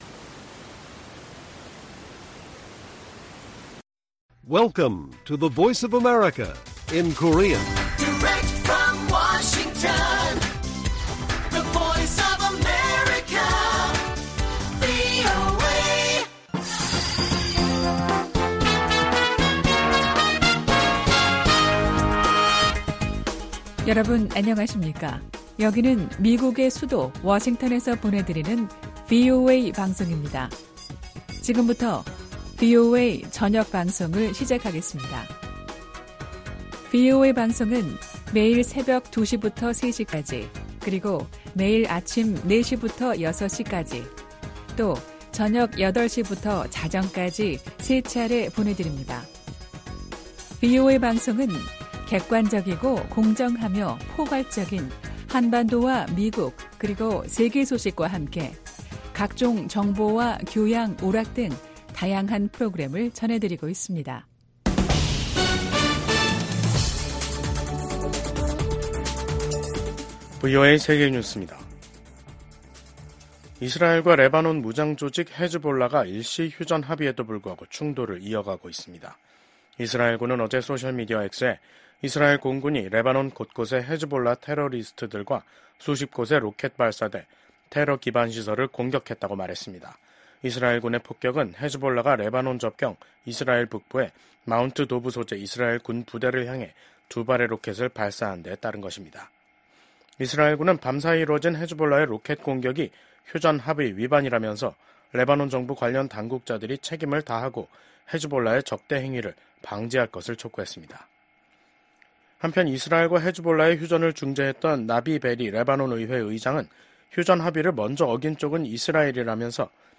VOA 한국어 간판 뉴스 프로그램 '뉴스 투데이', 2024년 12월 3일 1부 방송입니다. 미국 국무부는 북러 간 군사협력을 매우 우려한다며 북한군 파병 등에 대응해 우크라이나 방위력 강화를 위한 조치를 취할 것이라고 밝혔습니다. 러시아에 파병된 북한군이 아직 최전선에서 공격 작전에 참여하지 않고 있다고 미국 국방부가 밝혔습니다. 북한이 연말에 노동당 중앙위원회 전원회의를 열어 한 해를 결산합니다.